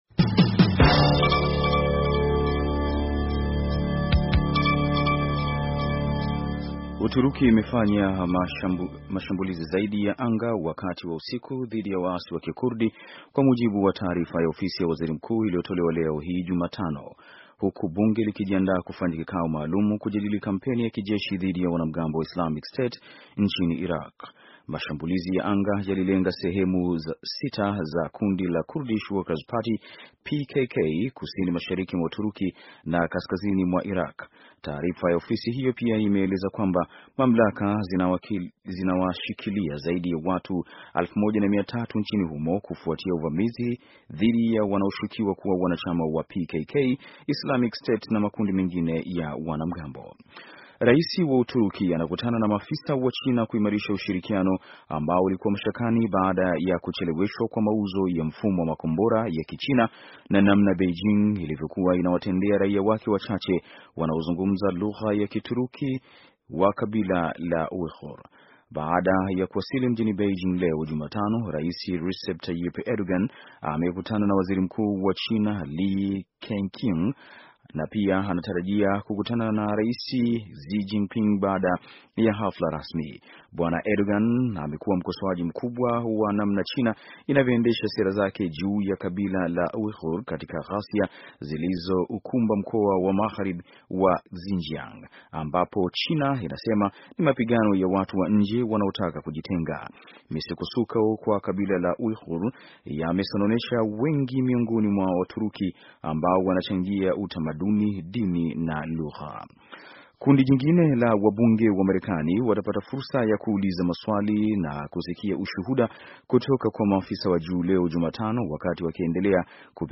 Taarifa ya habari - 5:00